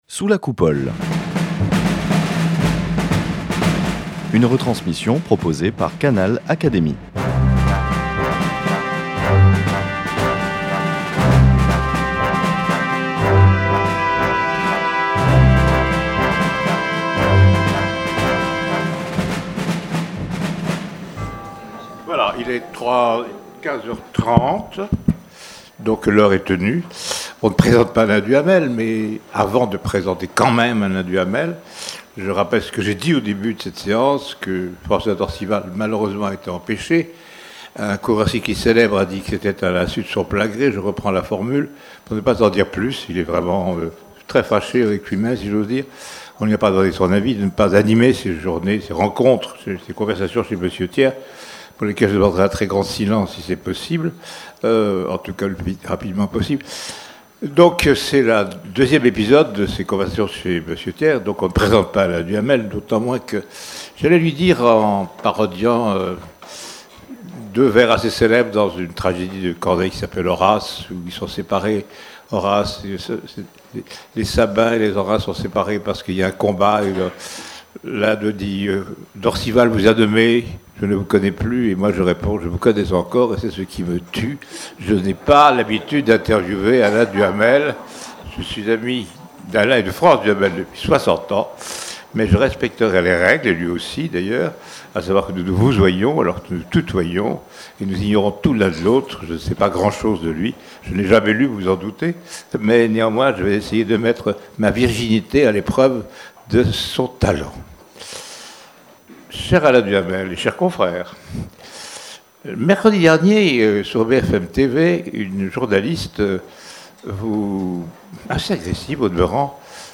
Allocution d’Alain Duhamel, membre de l’Académie des sciences morales et politiques